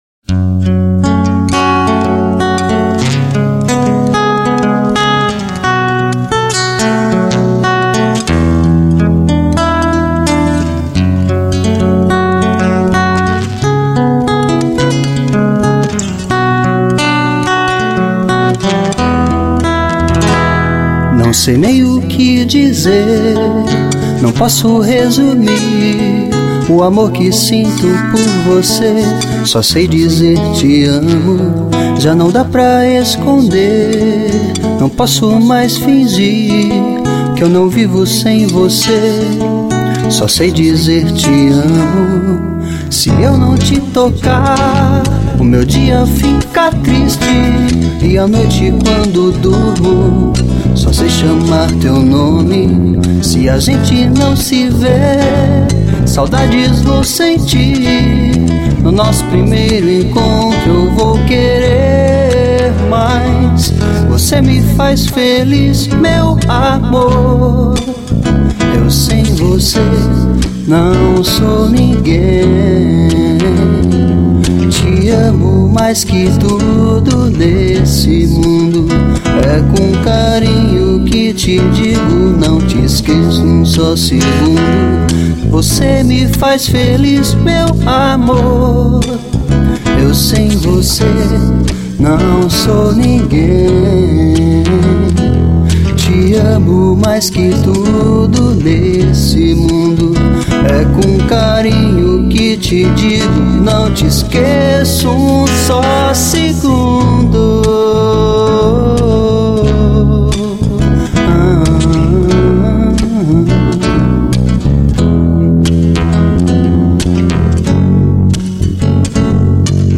violão
baixo